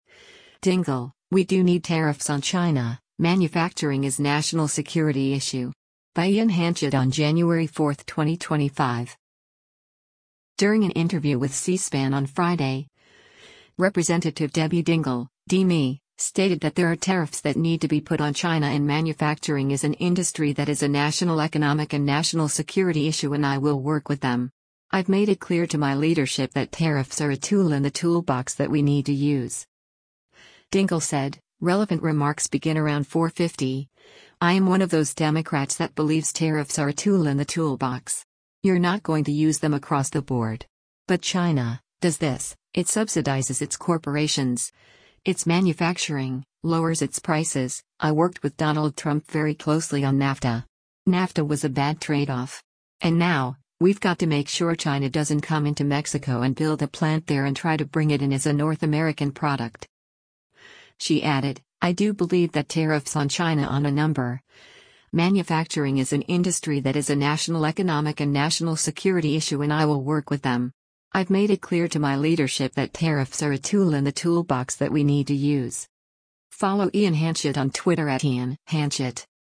During an interview with C-SPAN on Friday, Rep. Debbie Dingell (D-MI) stated that there are tariffs that need to be put on China and “manufacturing is an industry that is a national economic and national security issue and I will work with them. I’ve made it clear to my leadership that tariffs are a tool in the toolbox that we need to use.”